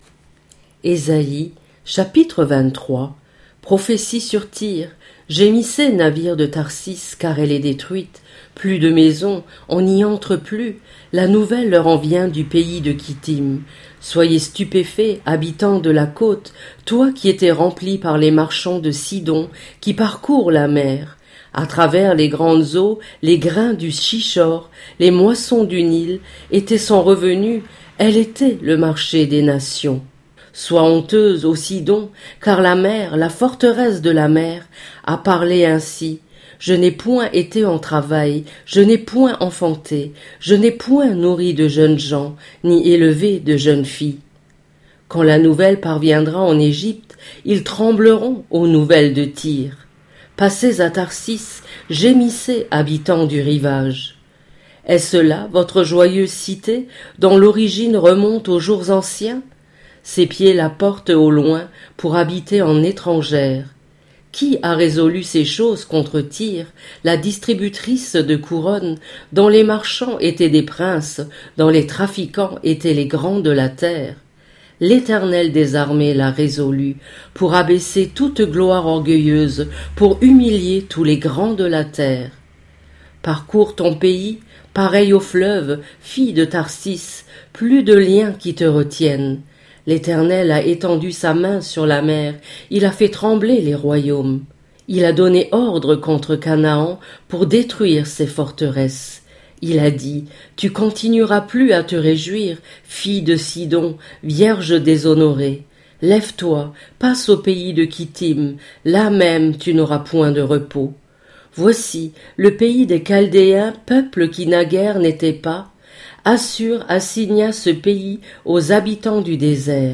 Livre audio